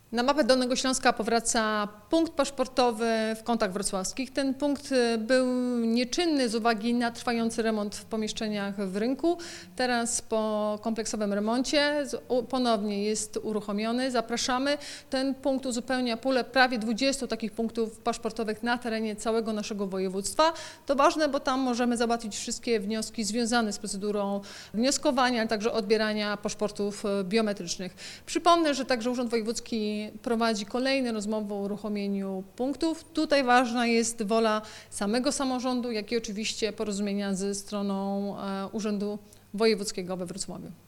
O ponownie otwartym punkcie paszportowym w budynku ratusza w Kątach Wrocławskich mówi wojewoda dolnośląska – Anna Żabska.